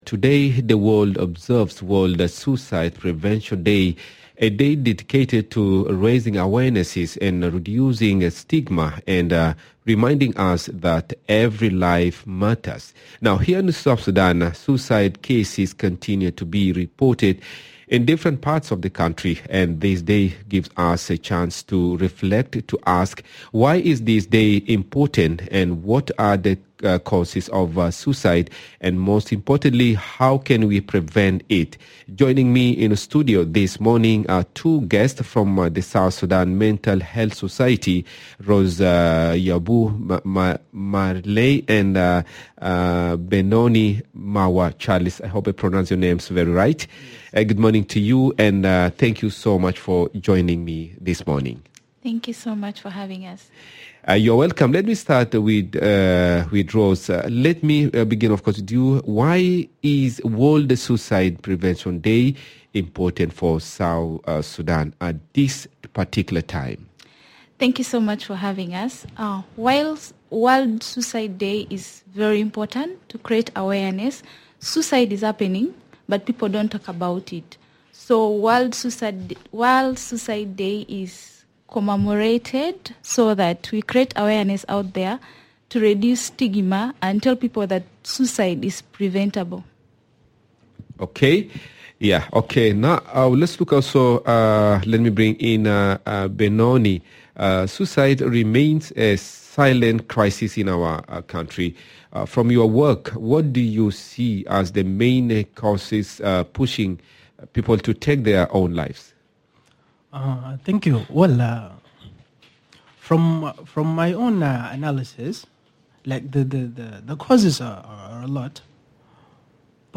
To help us explore these critical questions, we’re joined in the studio by two guests from the South Sudan Mental Health Society